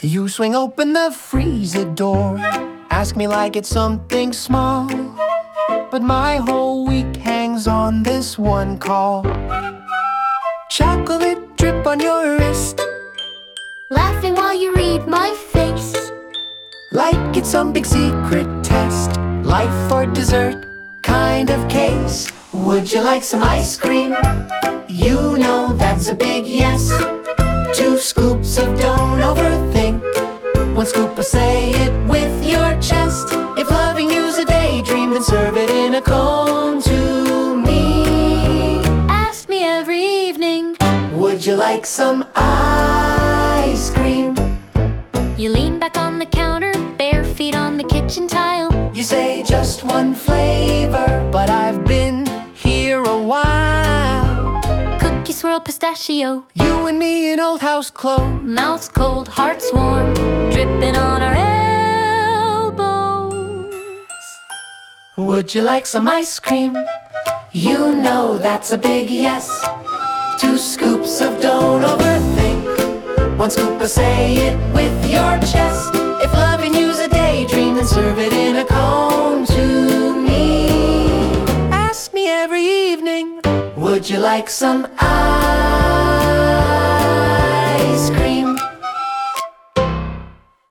Instrumental / 歌なし
弾むような軽いリズムは、ジュニア選手の元気なステップやジャンプにぴったり。